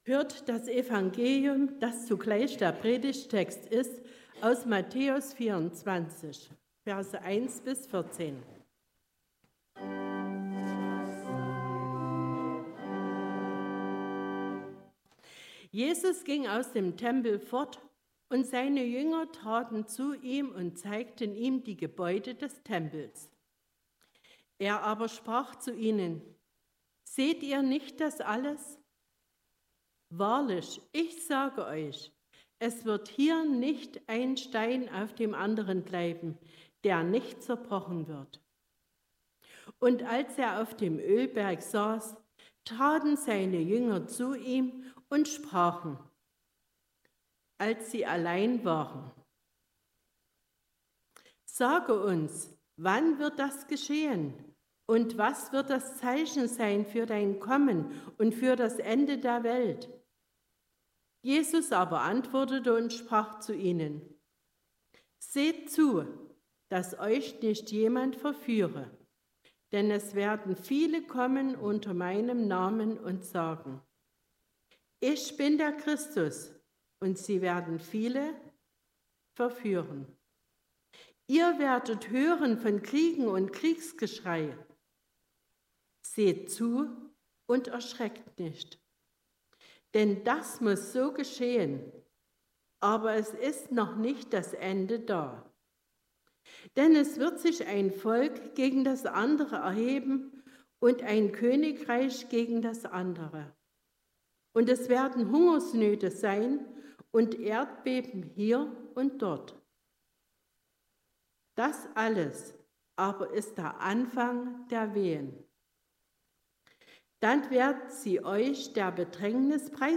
Passage: Matthäus 24, 1-14 Gottesdienstart: Predigtgottesdienst Obercrinitz Der Countdown bis Weihnachten läuft.